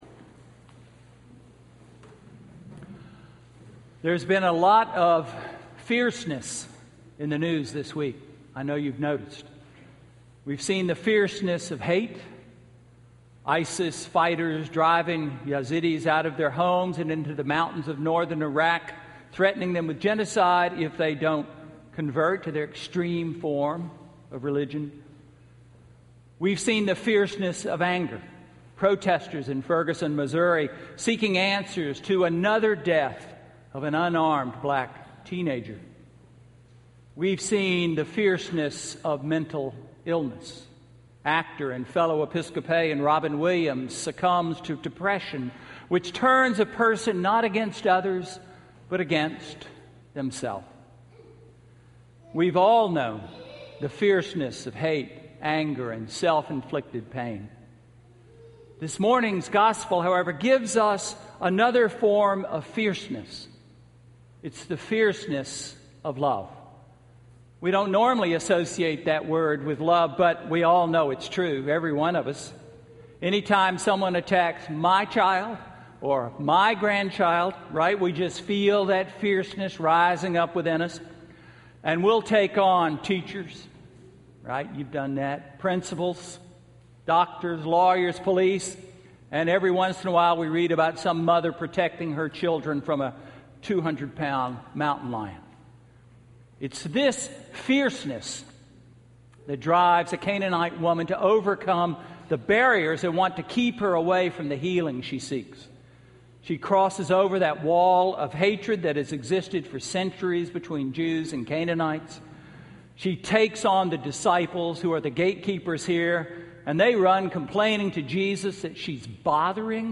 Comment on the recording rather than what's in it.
Sermon–August 17, 2014 – All Saints' Episcopal Church